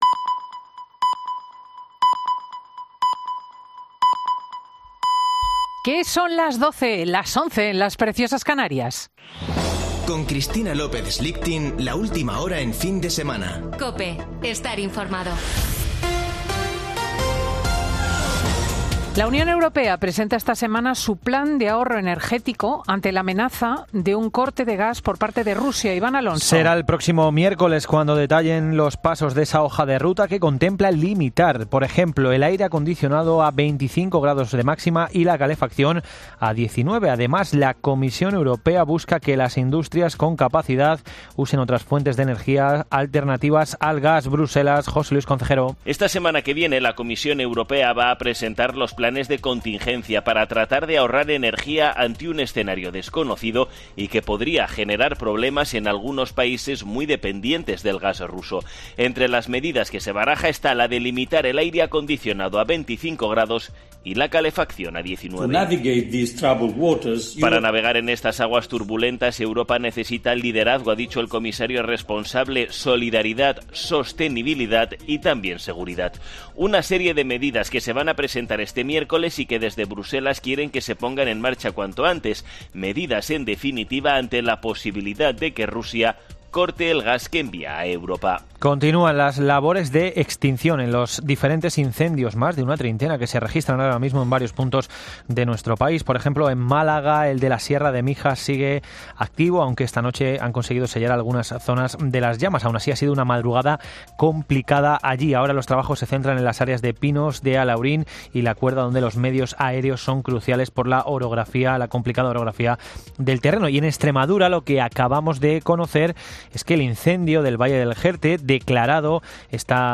Boletín de noticias de COPE del 17 de julio de 2022 a las 12:00 horas